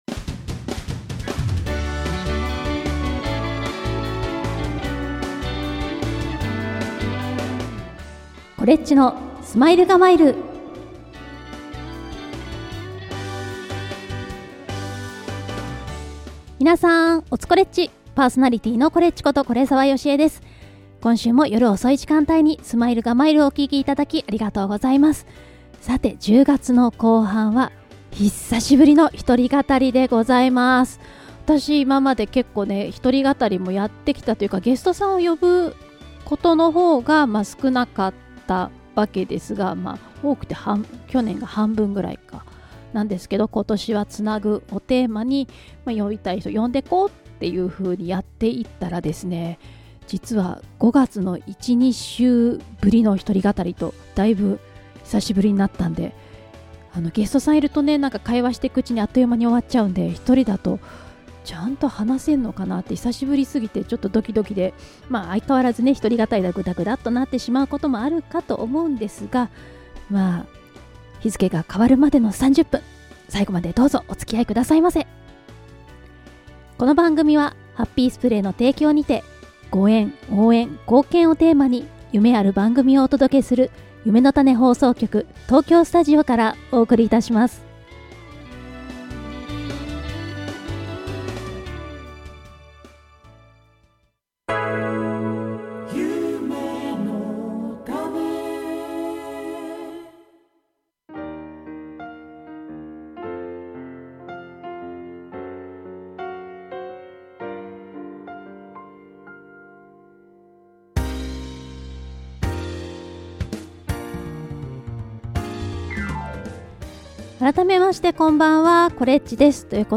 東京スタジオ
2月22日(日)のイベントの告知もしつつ、楽曲も2曲ご紹介♪ ▶4月前半放送(4月6日、4月13日) ゲストなしの1人語り。